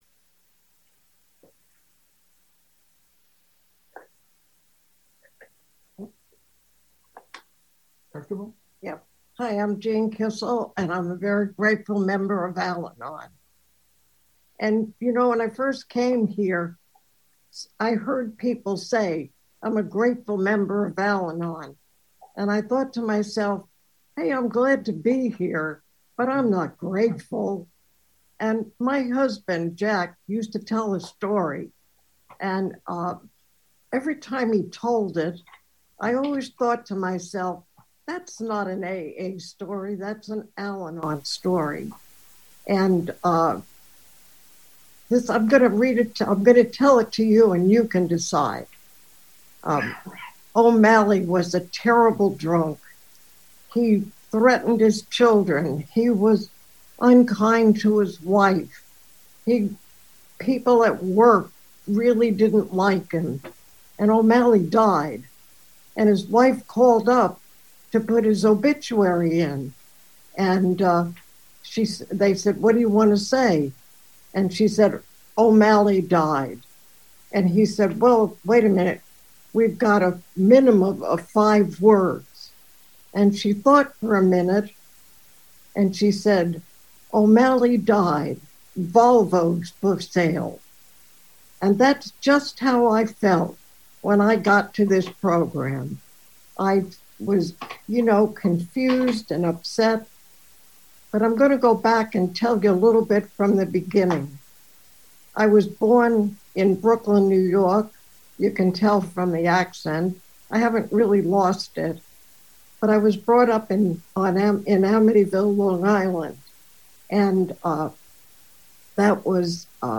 Southern California AA Convention